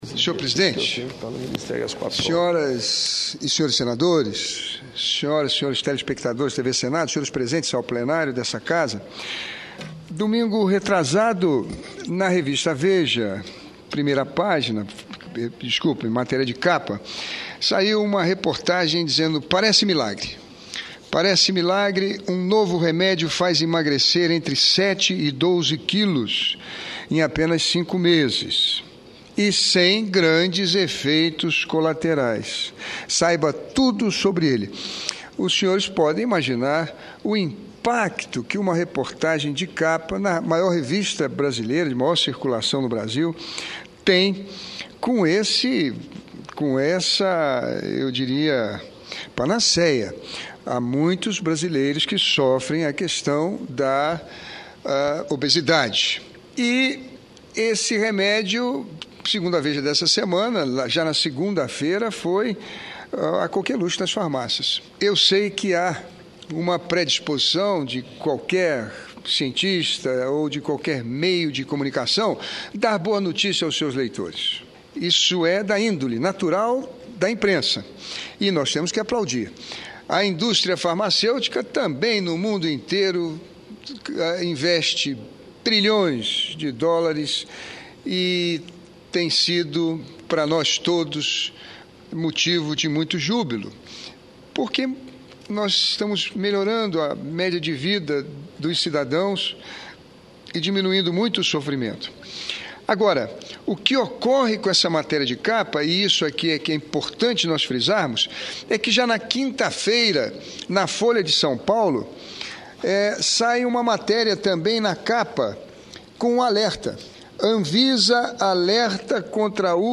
Plenário